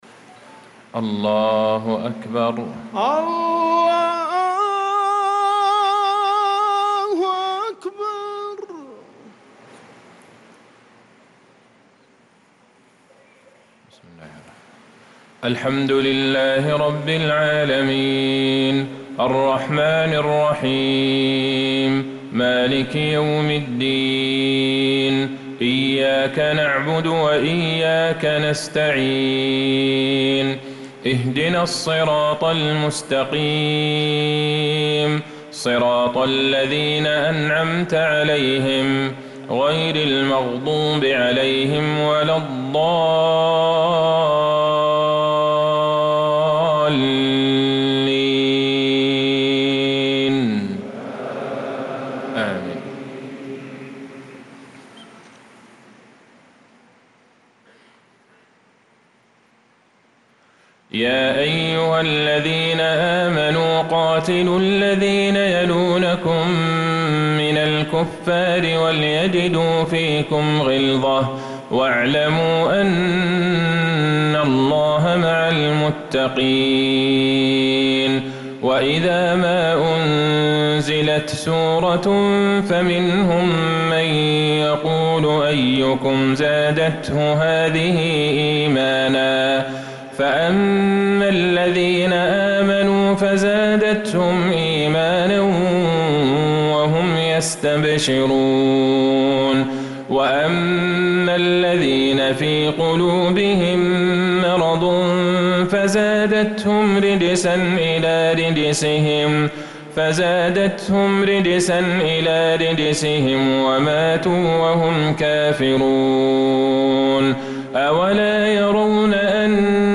صلاة العشاء